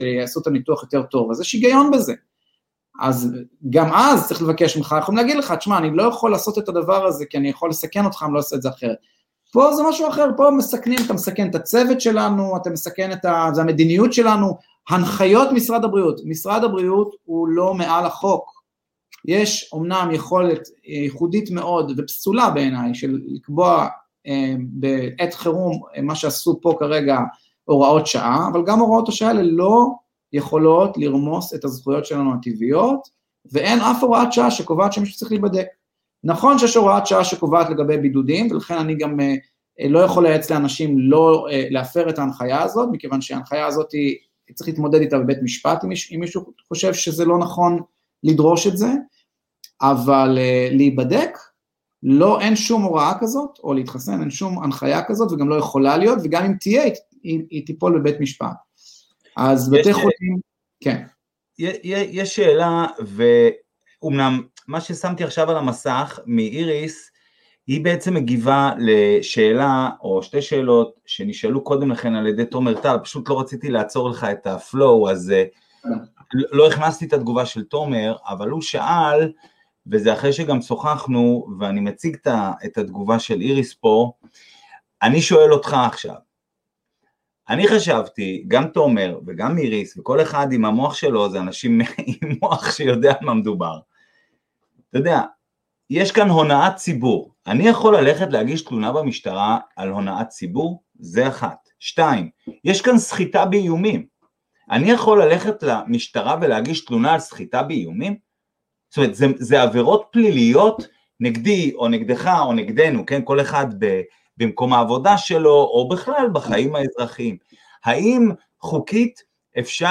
שיחה